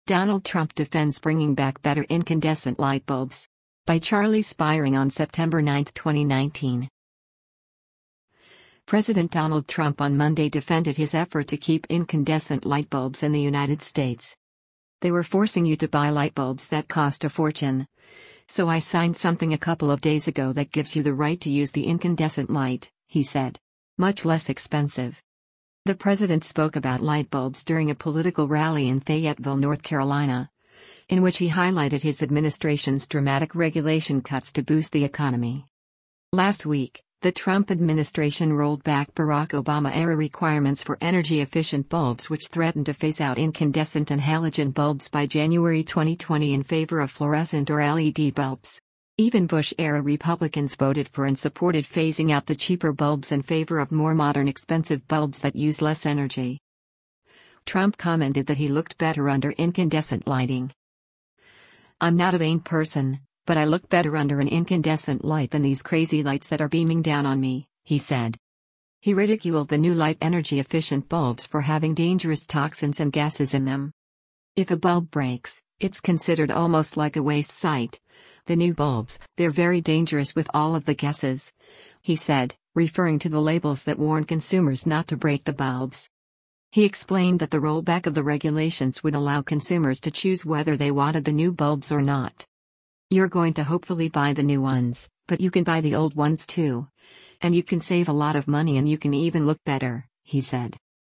The president spoke about light bulbs during a political rally in Fayetteville, North Carolina, in which he highlighted his administration’s dramatic regulation cuts to boost the economy.